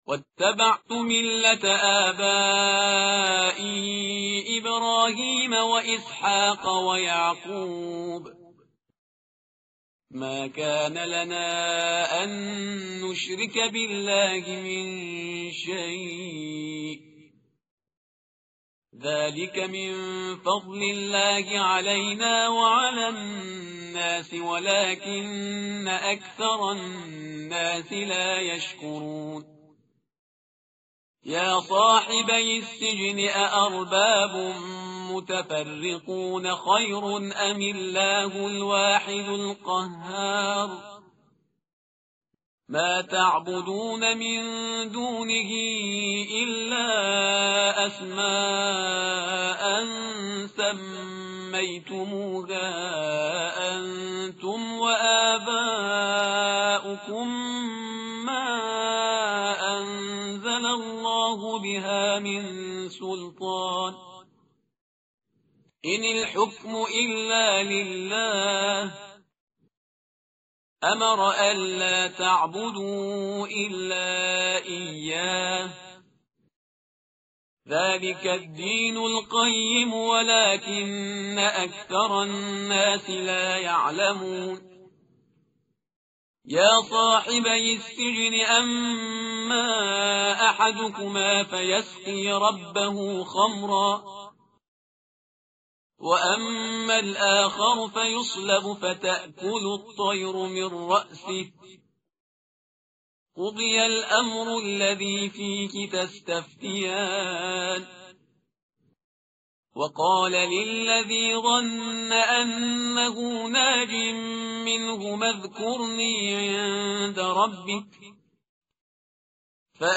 tartil_parhizgar_page_240.mp3